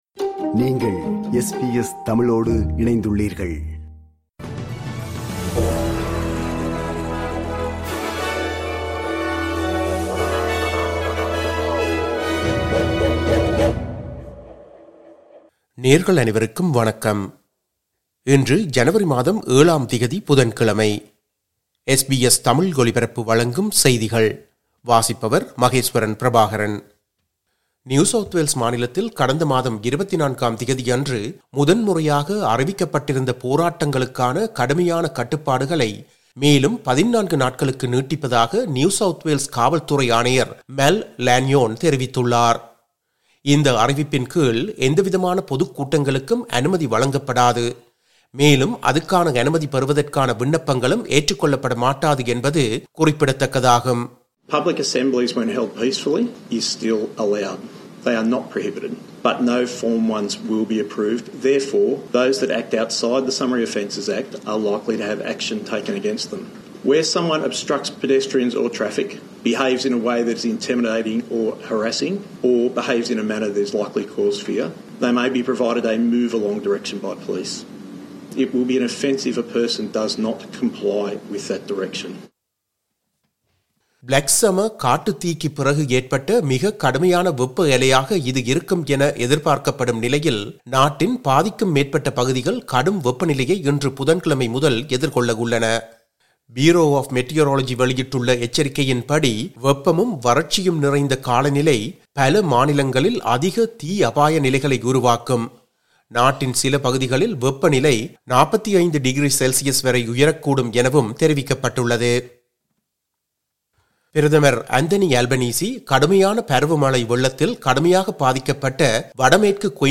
இன்றைய செய்திகள்: 07 ஜனவரி 2026, புதன்கிழமை.
SBS தமிழ் ஒலிபரப்பின் இன்றைய (புதன்கிழமை 07/01/2026) செய்திகள்.